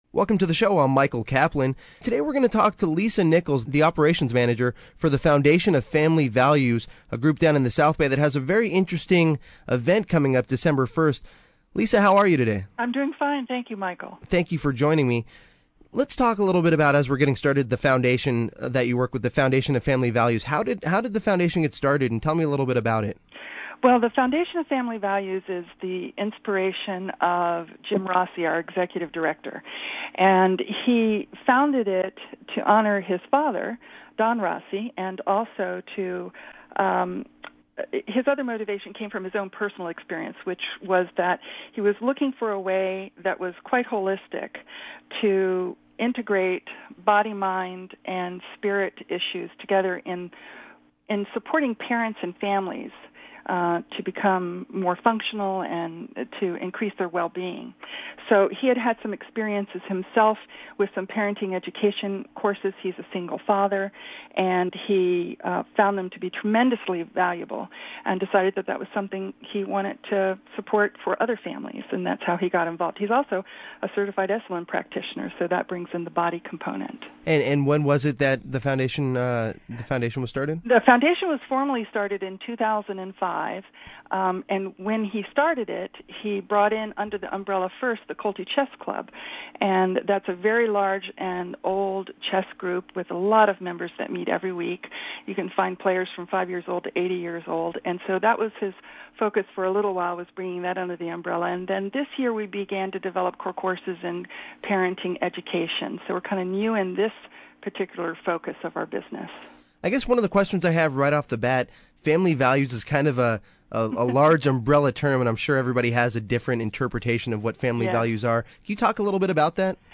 The 12 minute interview is available: Radio Show (download) 10/20/07 The Santa Clara All-Stars Paintball team won Silver Medals in the '07 Police Olympics !